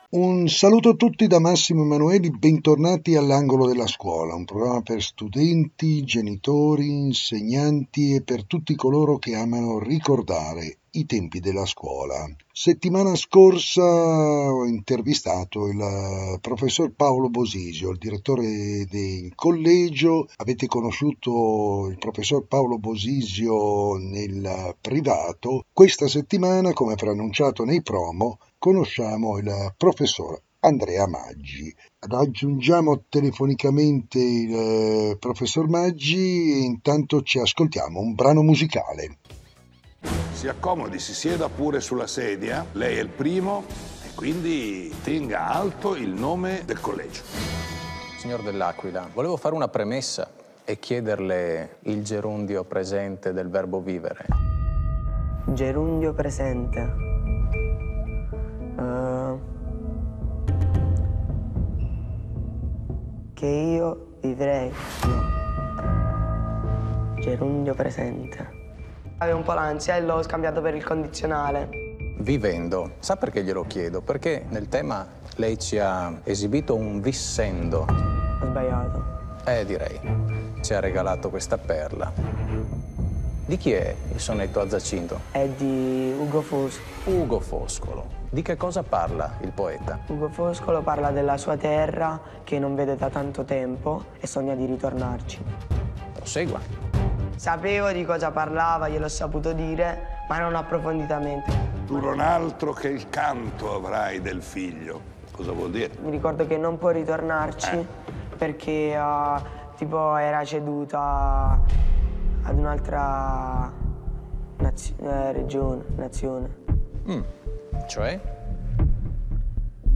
Andrea Maggi sarà ospite della trasmissione radiofonica L’angolo della scuola